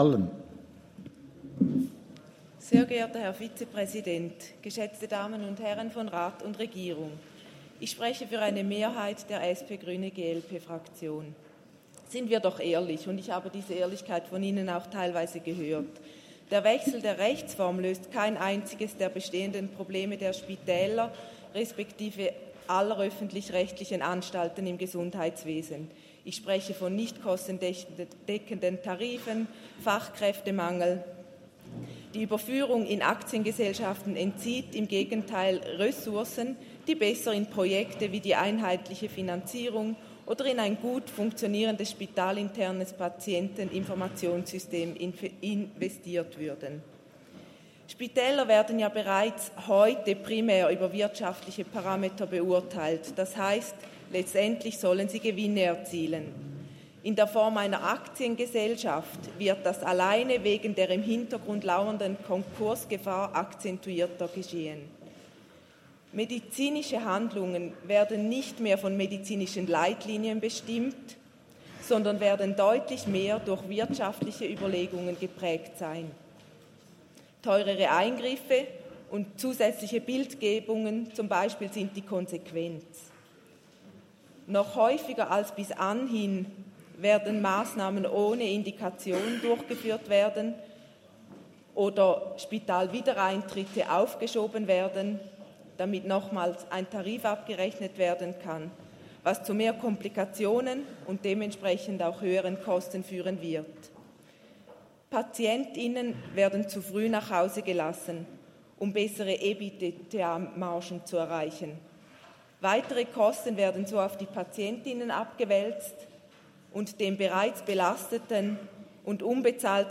Geschäft 42.24.05 des Kantonsrates St.Gallen